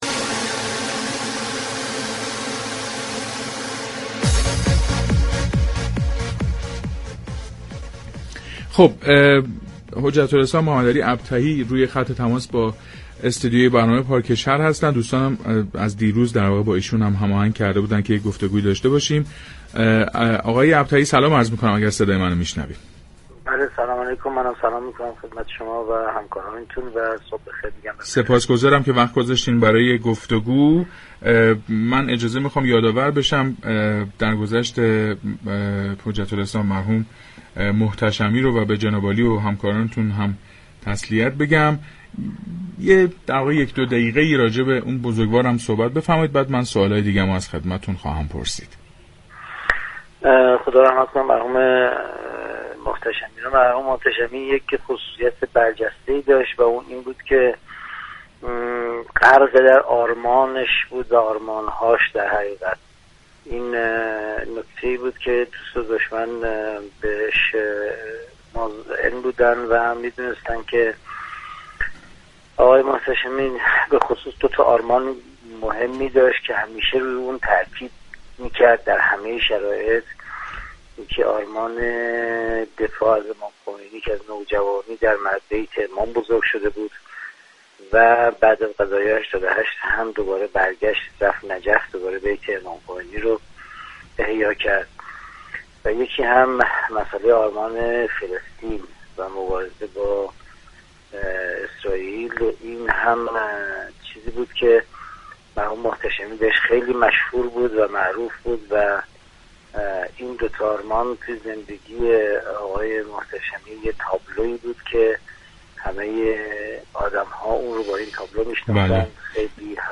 به گزارش پایگاه اطلاع رسانی رادیو تهران، حجت الاسلام سیدمحمدعلی ابطحی رییس دفتر، رییس جمهوری اسبق جمهوری اسلامی ایران در گفتگو با برنامه پارك شهر درگذشت حجت الاسلام سیدعلی اكبر محتشمی‌پور، سفیر پیشین ایران در سوریه را تسلیت گفت و در خصوص وی افزود: مرحوم محتشمی پور ویژگی‌های برجسته ای داشت.